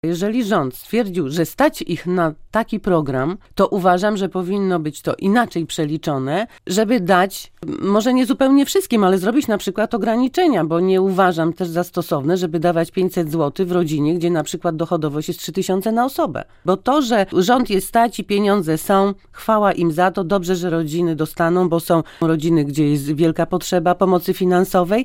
W Porannych Pytaniach Radia Olsztyn mówiła, że nie wszystkie rodziny powinny korzystać z tego programu: